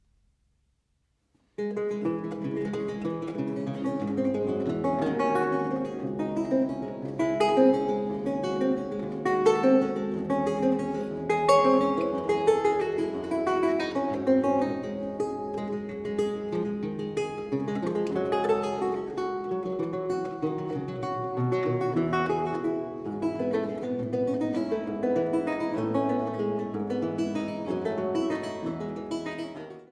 Laute